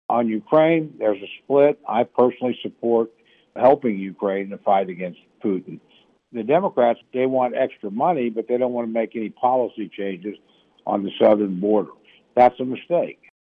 CLICK HERE to listen to commentary from Congressman Tom Cole.